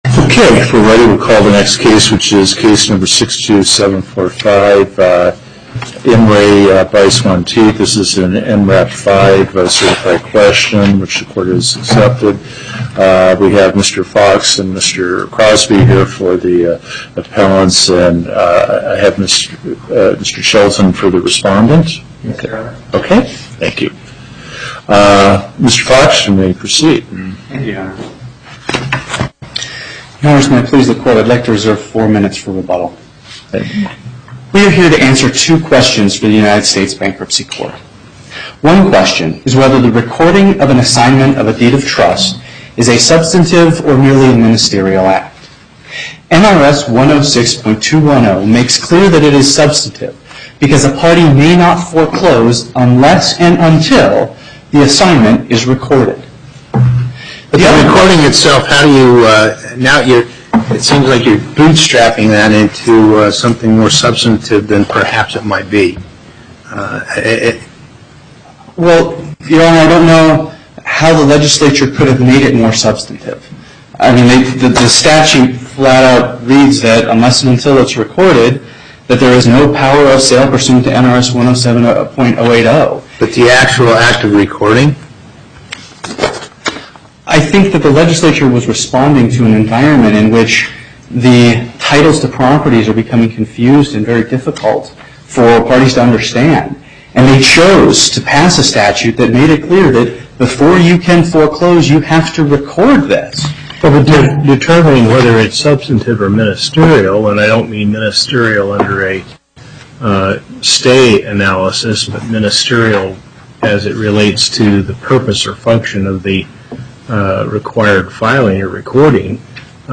Location: Las Vegas Before the En Banc Panel, Chief Justice Gibbons Presiding